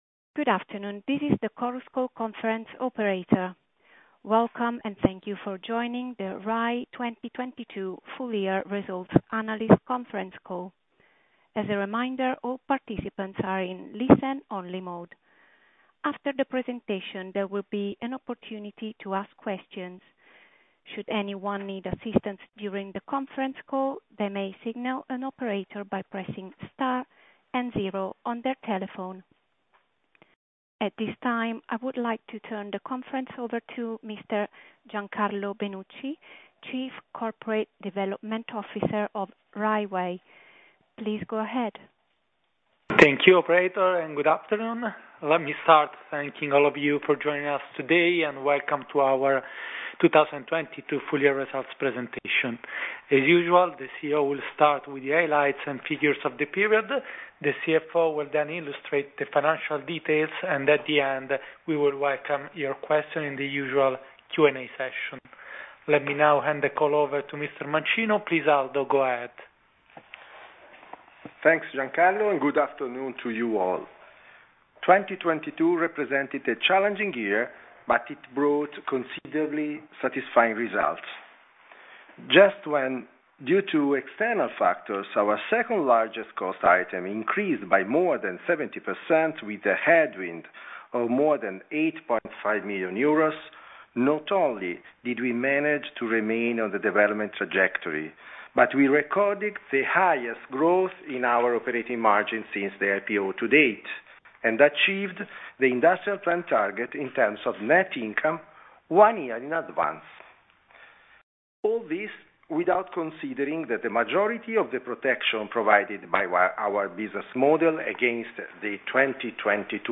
Audio mp3 Conference call Risultati 2022FY